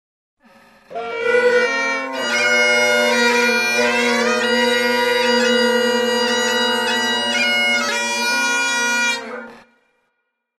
Насмешка неумелая игра на трубе